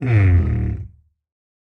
minecraft / sounds / mob / sniffer / idle5.ogg